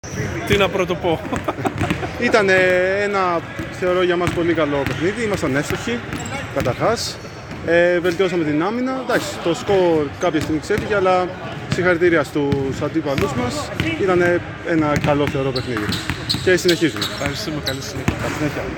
GAMES INTERVIEWS
Παίκτης  Deree